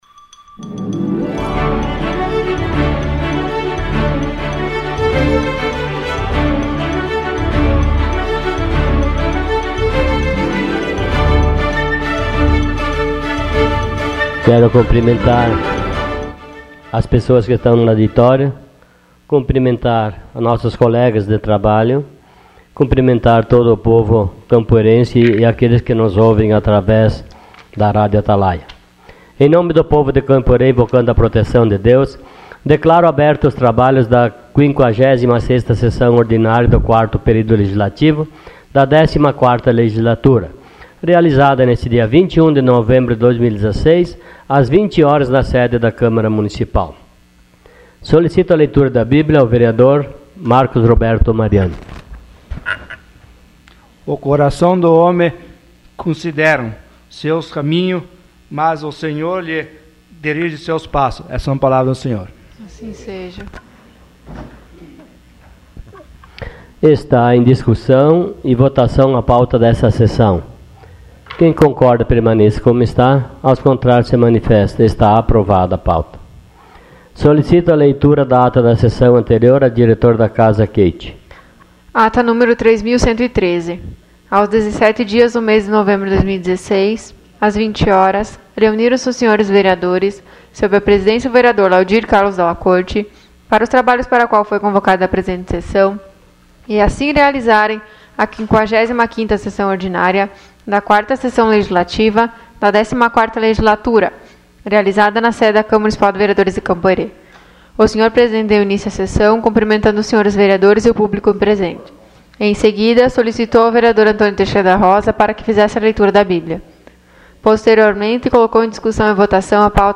Sessão Ordinária dia 21 de novembro de 2016.